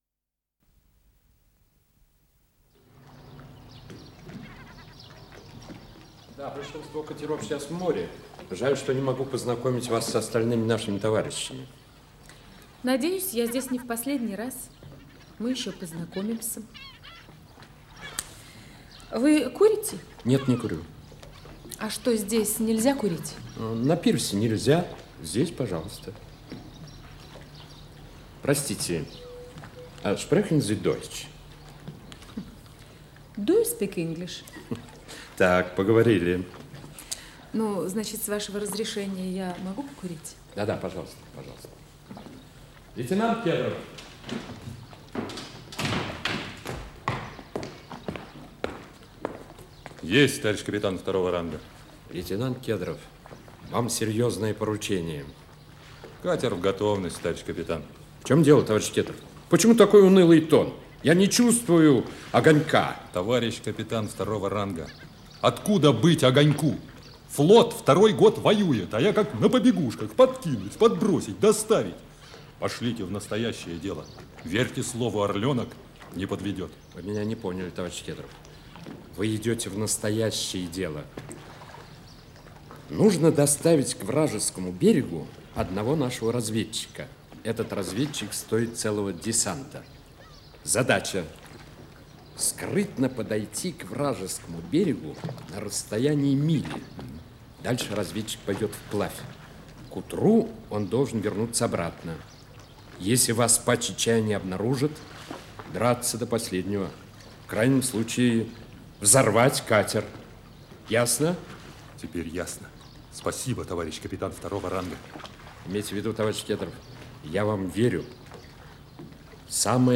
Исполнитель: Артисты Центрального академического театра Советской армии Вокальный квартет
Радиокомпозиция спектакля